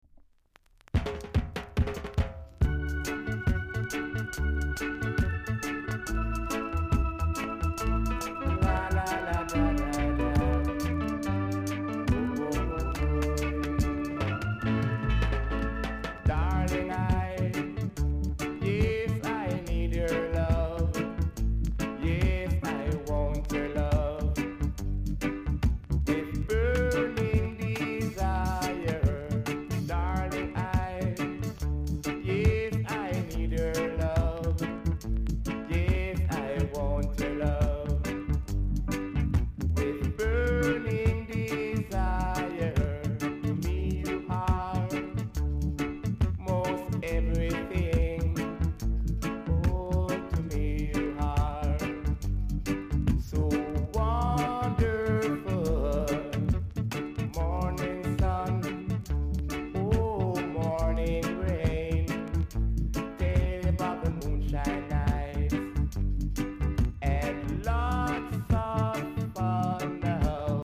※多少小さなノイズはありますが概ね良好です。
コメント RARE REGGAE VOCAL!!※VERSIONのフェードアウト前で少しプチパチします。